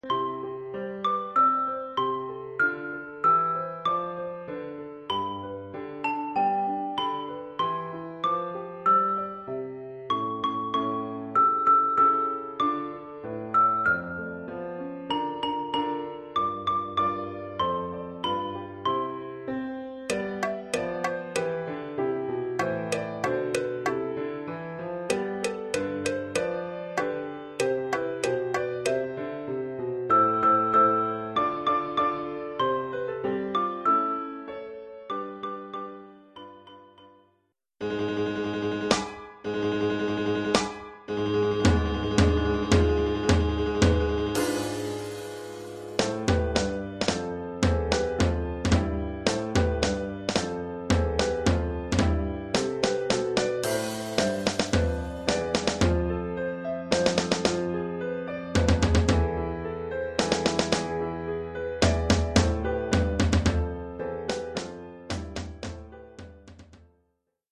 Oeuvre en deux mouvements,
Nomenclature : Xylophone et 2 Temples block.
Nomenclature : Cymbales, Caisse claire, Tom basse.